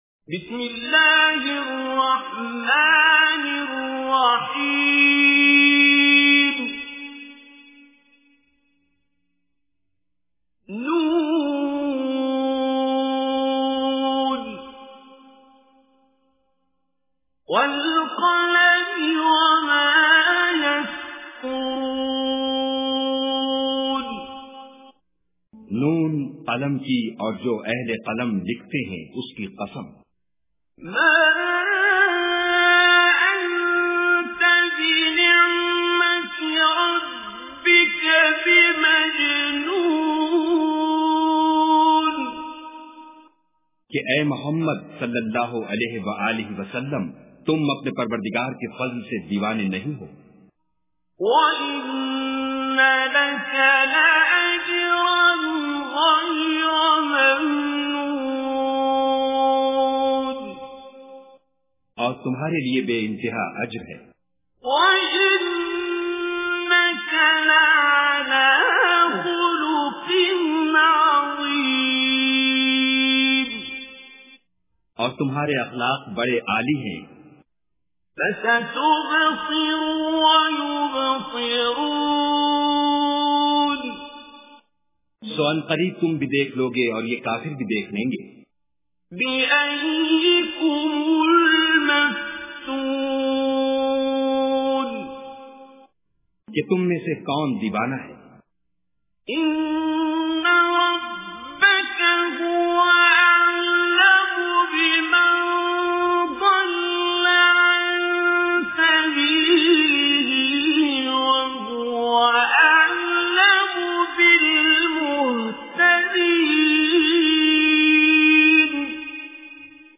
Surah Al-Qalam Recitation with Urdu Translation
Listen online and download mp3 tilawat / recitation of Surah Qalam in the beautiful voice of Qari Abdul Basit As Samad.